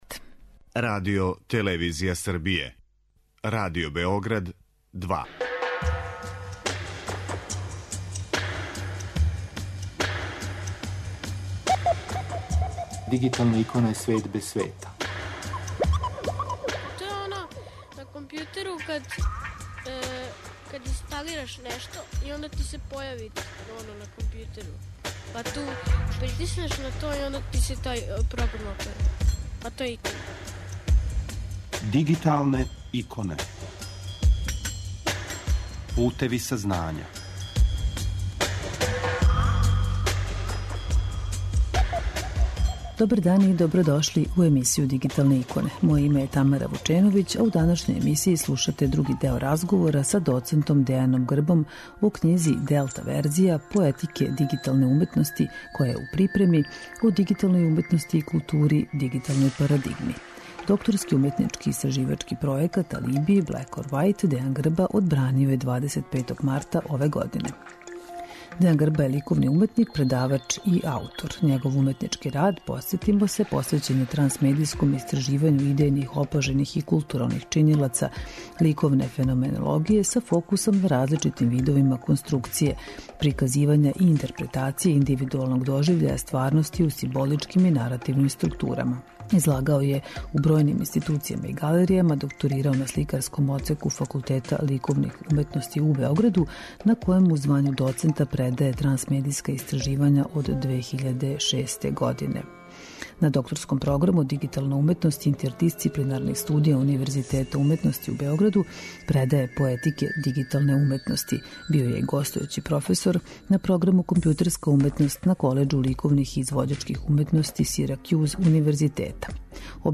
У овој емисији слушаћете други део разговора